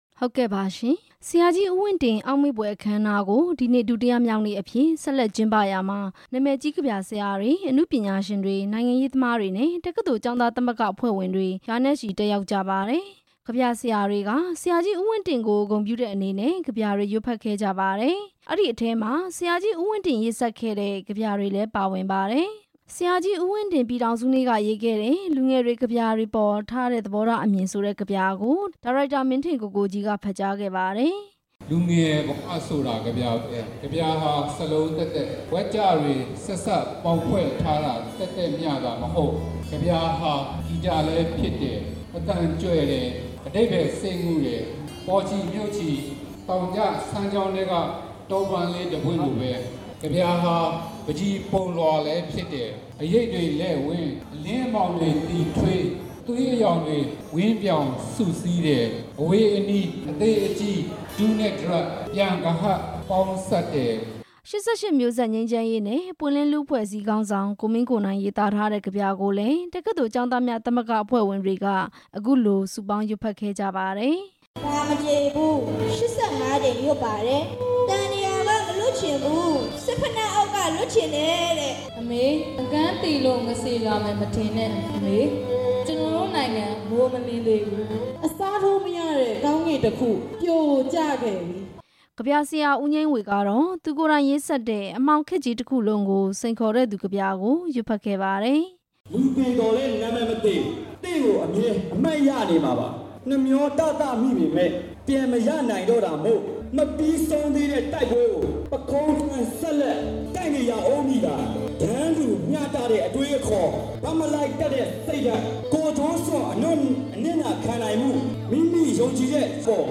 ယုဒဿန်ရိပ်သာမှာကျင်းပတဲ့ အောက်မေ့ဖွယ် အခမ်းအနား